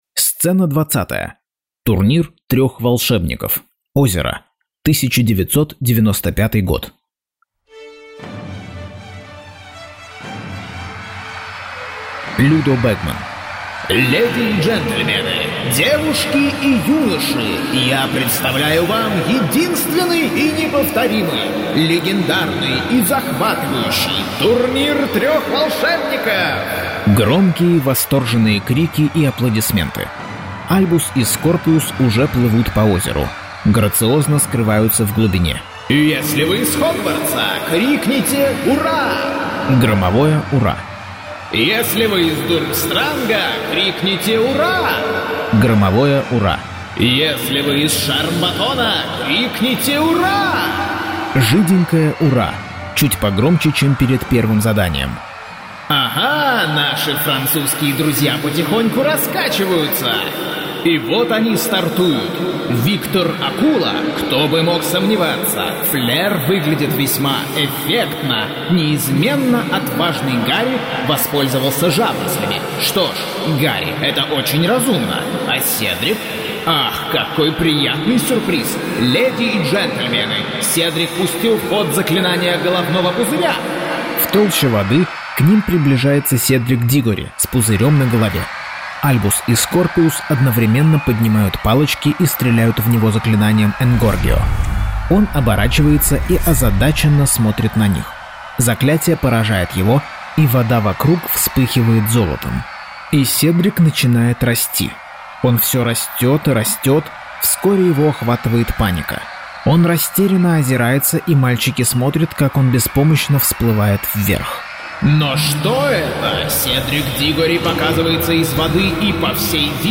Аудиокнига Гарри Поттер и проклятое дитя. Часть 32.